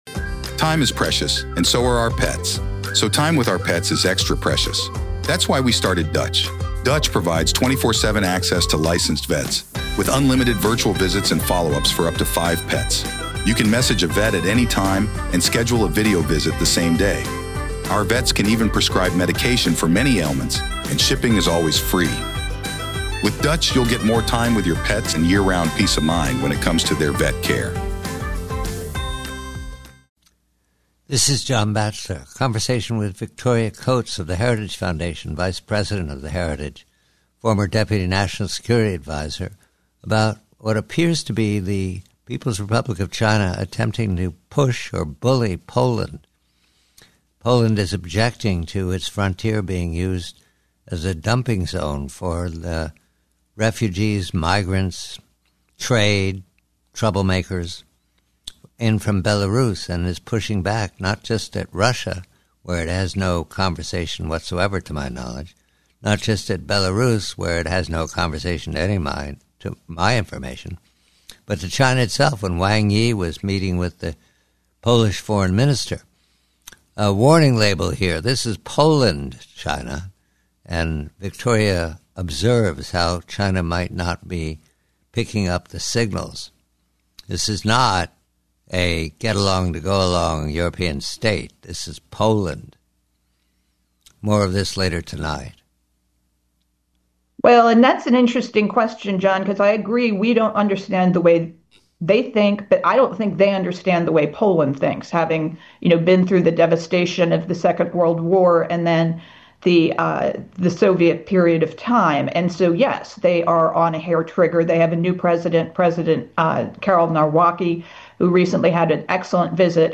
Headliner Embed Embed code See more options Share Facebook X Subscribe PREVIEW: HEADLINE: China's Misstep with Poland: A Strong European State Pushes Back GUEST NAME: Victoria Coates SUMMARY: John Batchelor converses with Victoria Coates regarding China's attempts to pressure Poland concerning border issues with Belarus . Poland is pushing back against China , asserting its strong independence, expanding economy, and significant military armament, partly from the US . Coates suggests China may misunderstand Poland's resolve, making this a potential misstep. 1920 WARSAW